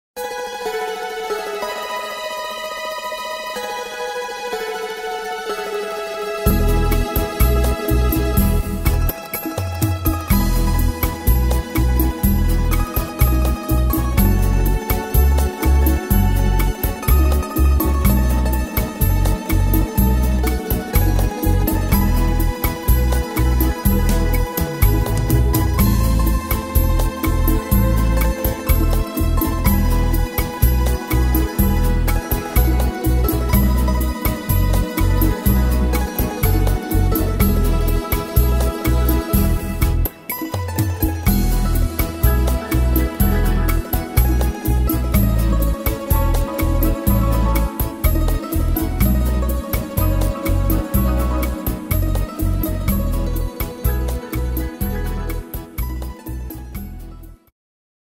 Tempo: 124 / Tonart: E-Dur